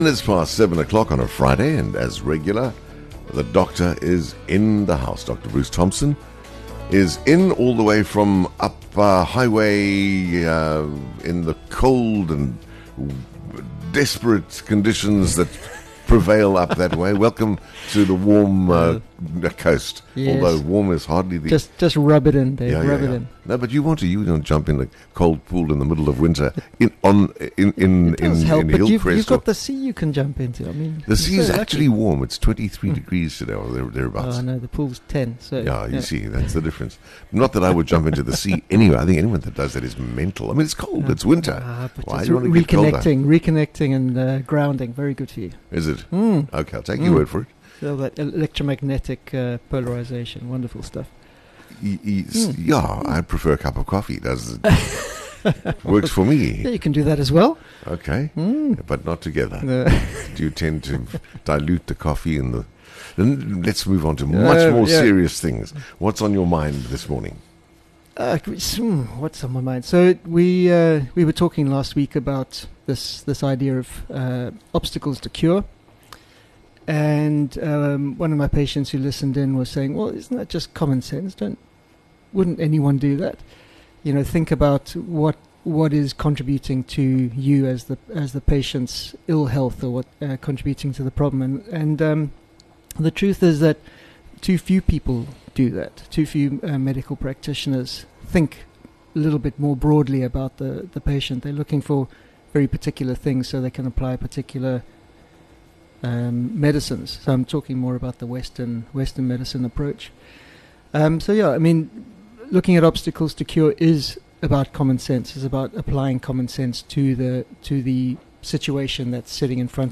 Join us as we interview